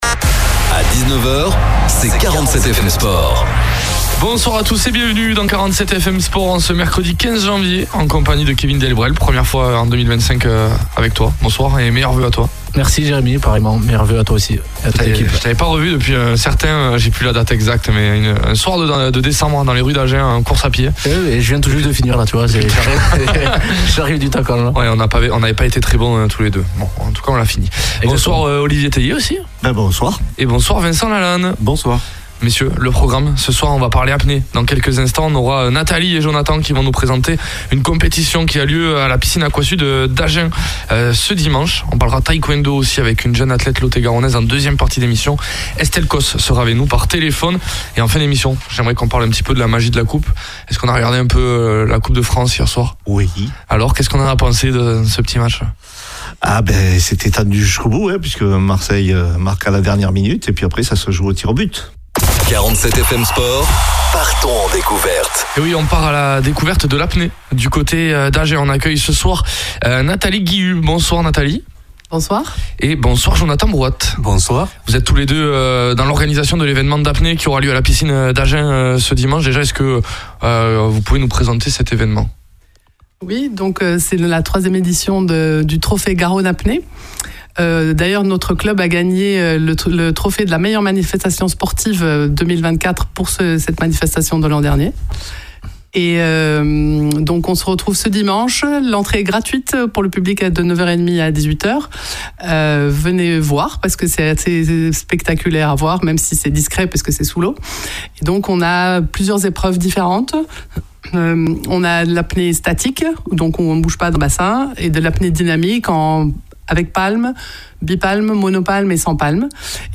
Interview 47FM 16/01/2025